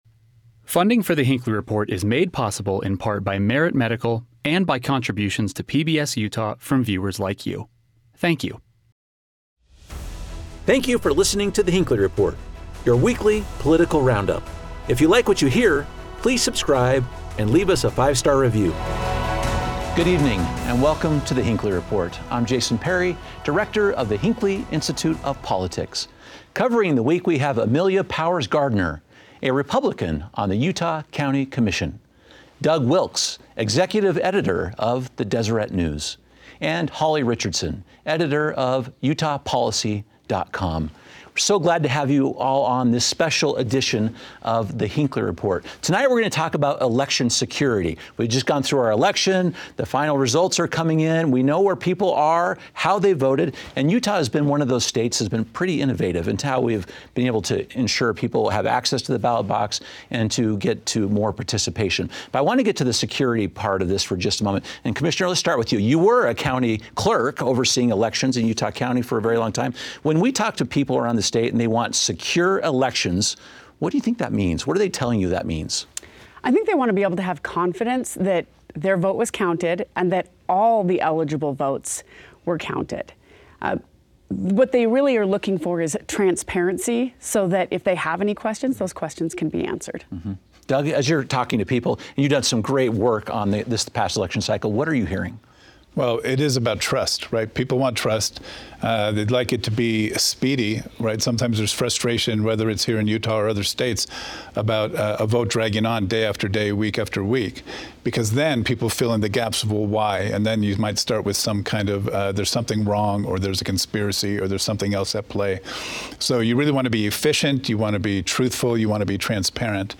In this episode, our expert panel takes a close look at the safeguards in place to protect election systems.